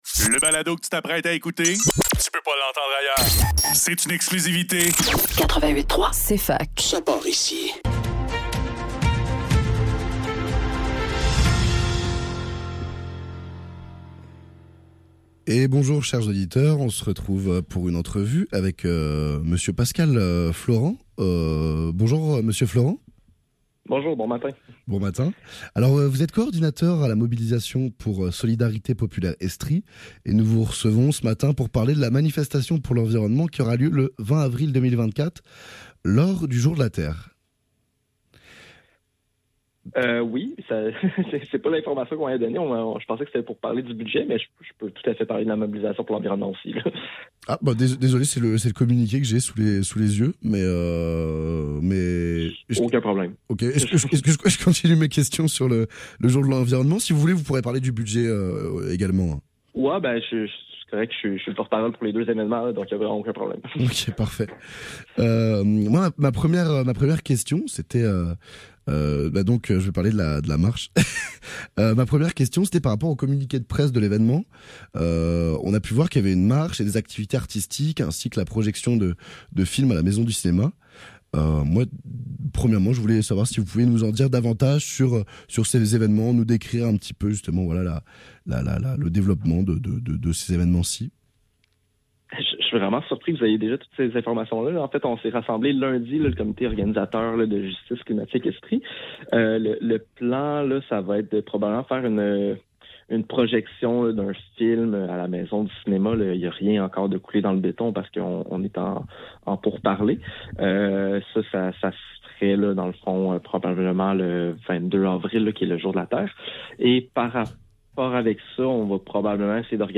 Le NEUF Le NEUF - Entrevue